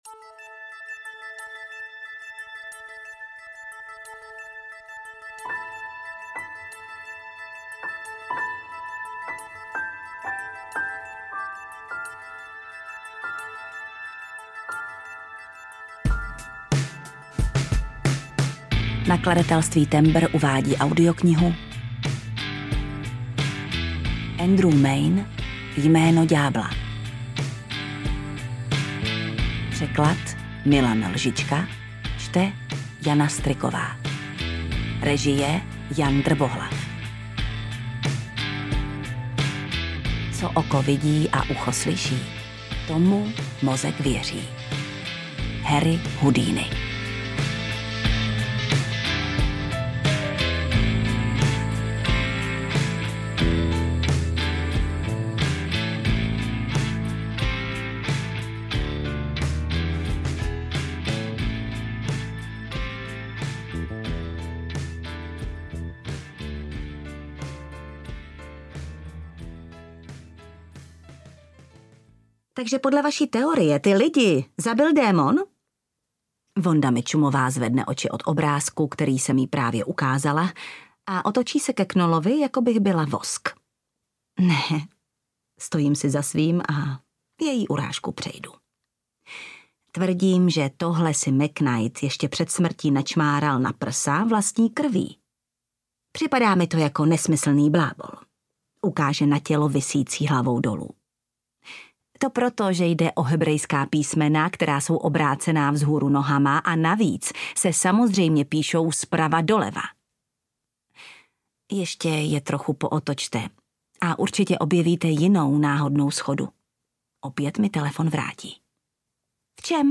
Jméno ďábla audiokniha
Ukázka z knihy
• InterpretJana Stryková